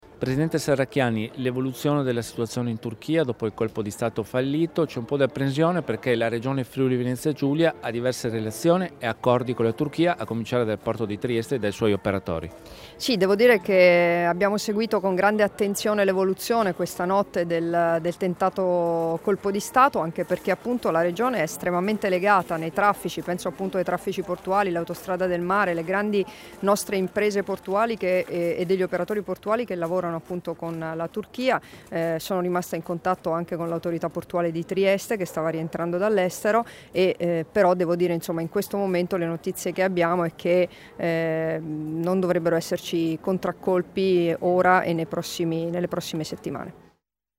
Dichiarazioni di Debora Serracchiani (Formato MP3) [770KB]
sul tentato colpo di Stato in Turchia, rilasciate a Cividale del Friuli (UD) il 16 luglio 2016